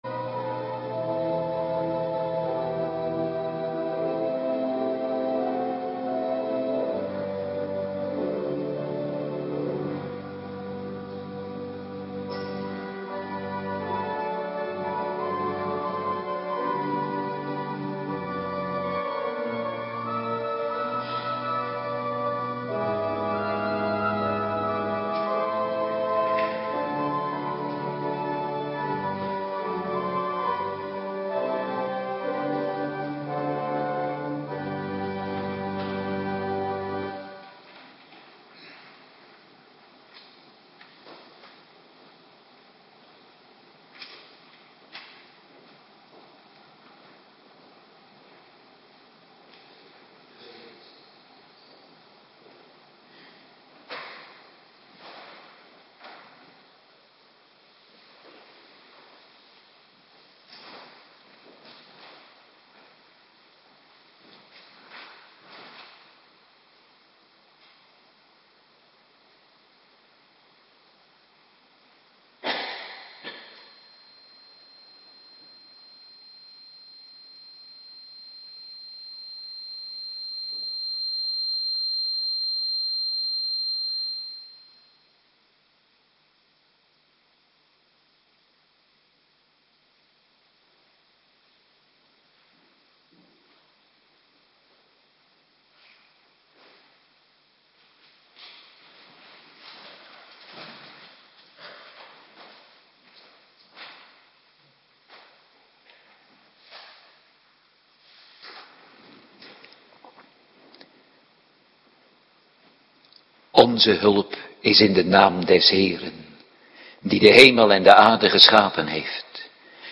Morgendienst Goede Vrijdag
Locatie: Hervormde Gemeente Waarder